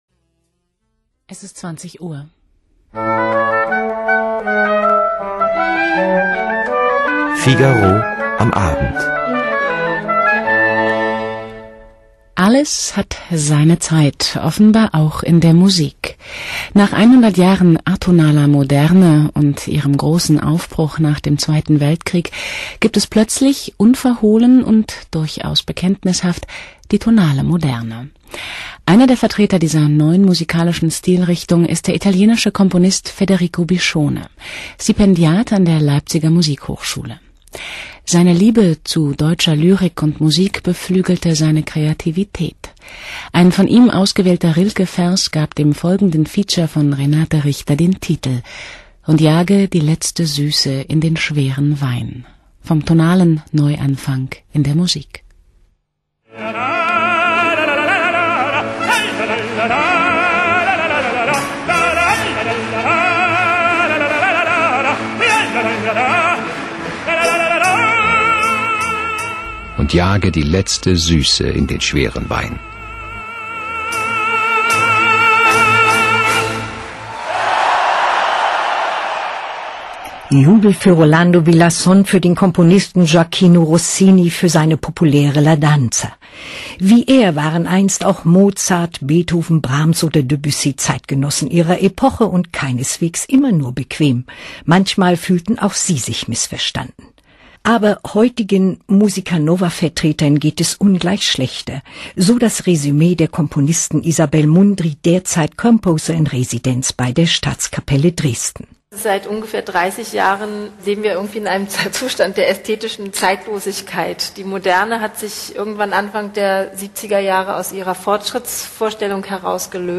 Ritratto radiofonico con intervista ed esempi musicali (MDR Figaro, Halle)